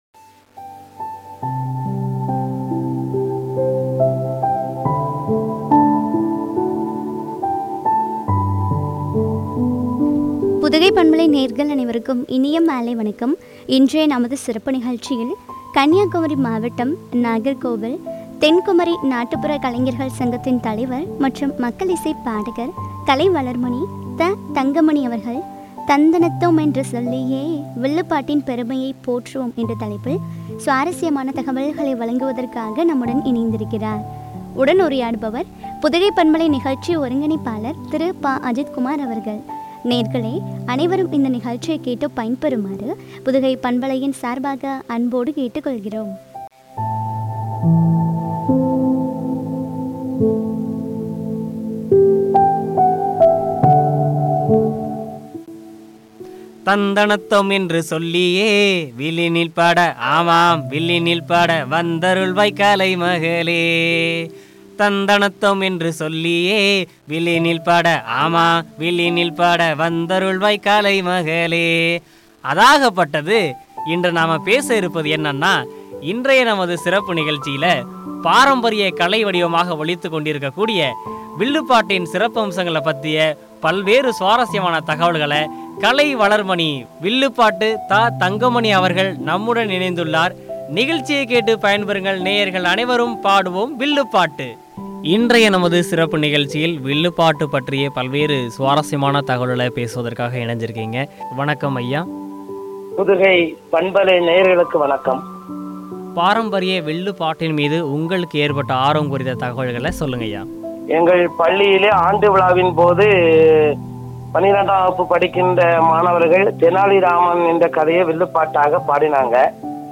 குறித்து வழங்கிய உரையாடல்.